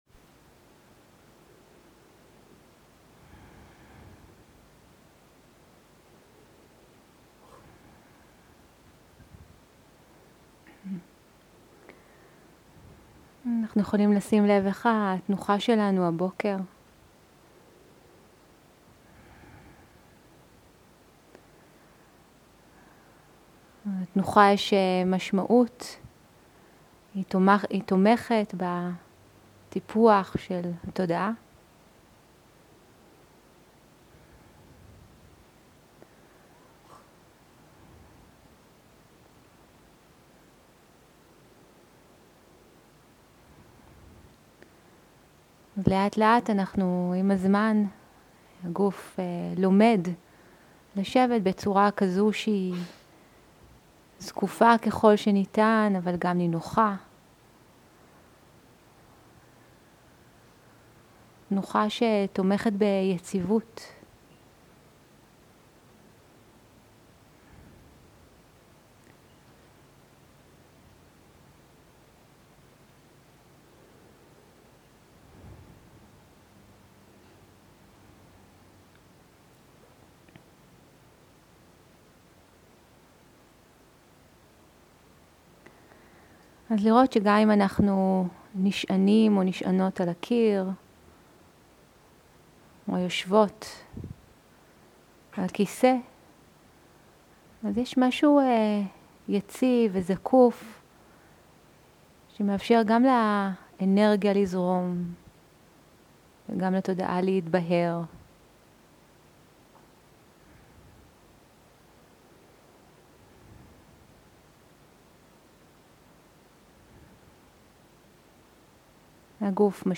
סוג ההקלטה: שיחת הנחיות למדיטציה
עברית איכות ההקלטה: איכות גבוהה מידע נוסף אודות ההקלטה